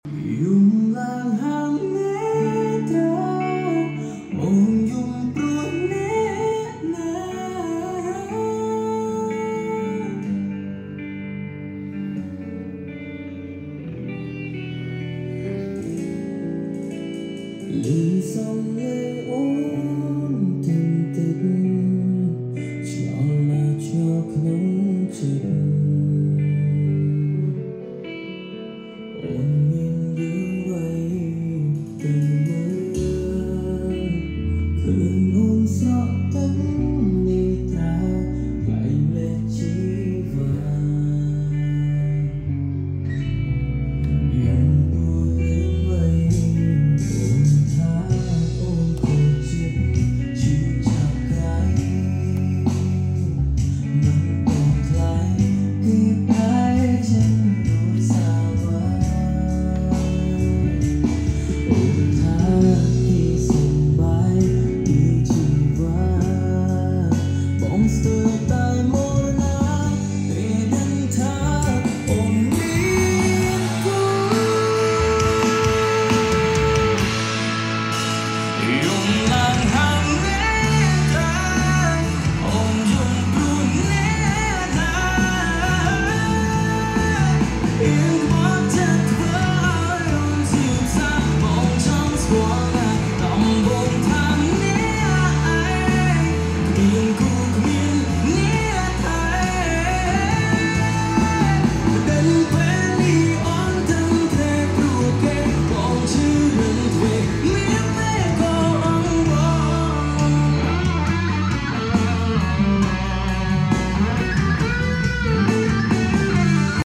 តេស្តសំឡេង Column Speaker Mackie SRM-Flex